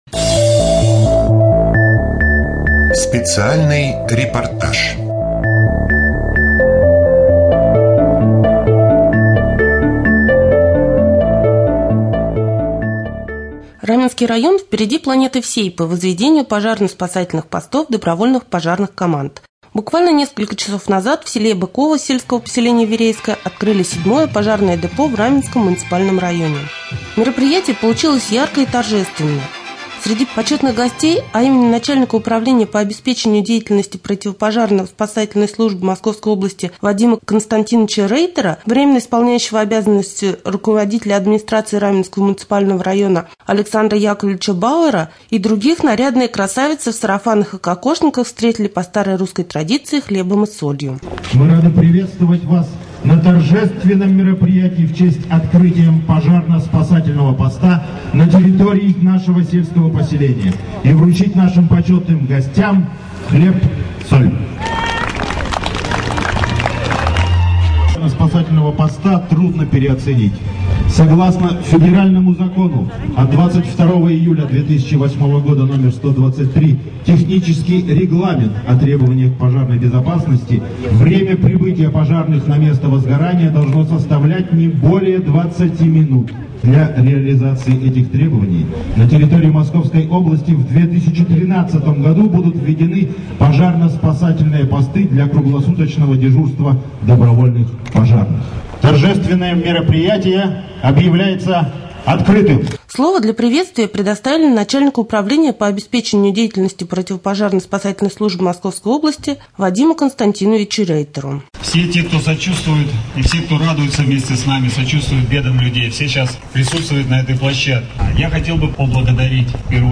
24.10.2013г. в эфире раменского радио - РамМедиа - Раменский муниципальный округ - Раменское